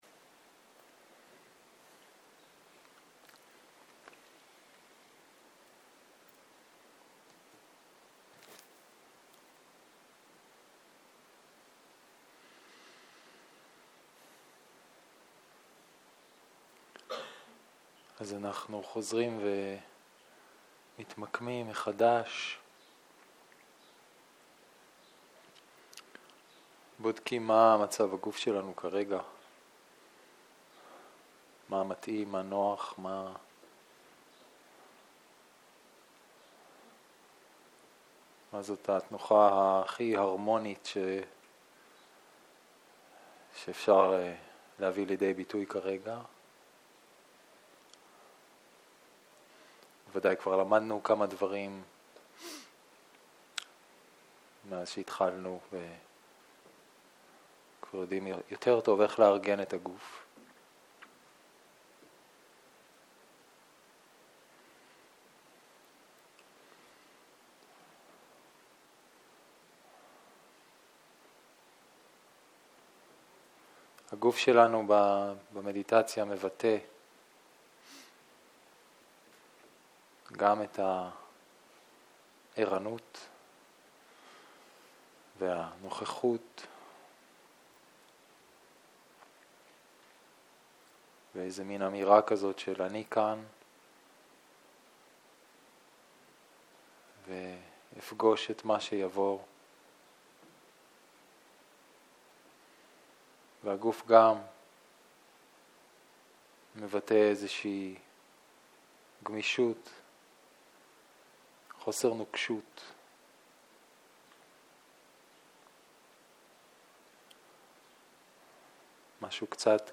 צהריים - מדיטציה מונחית
סוג ההקלטה: מדיטציה מונחית